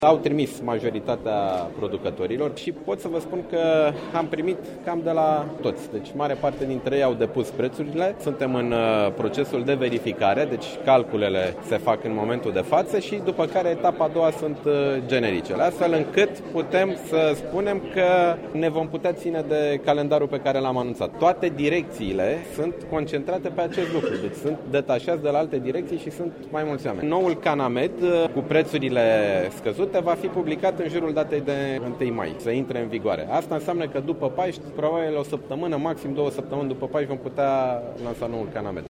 Ministrul sanatatii, Nicolae Banicioiu a declarat saptamana trecuta că în cazul în care negocierile dintre CNAS şi producătorii de medicamente vor decurge bine, pacienţii diagnosticaţi cu hepatita C vor putea beneficia de tratament fără interferon în jurul datei de 1 mai.